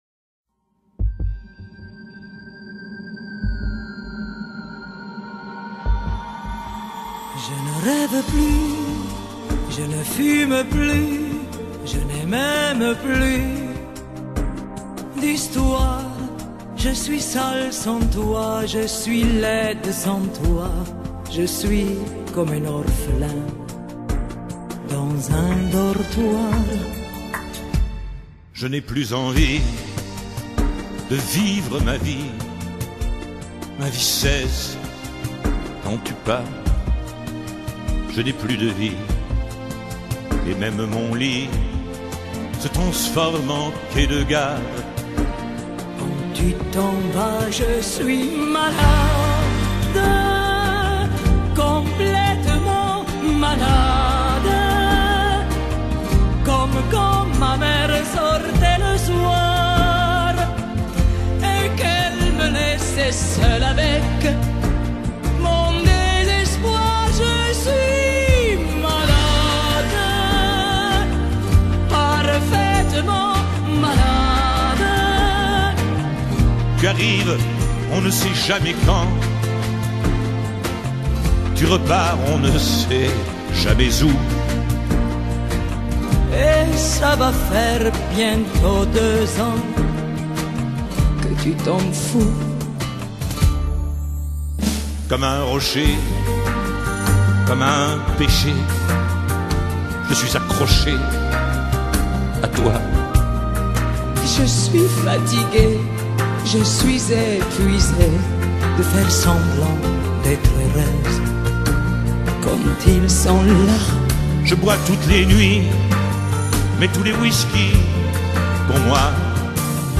un duo virtuel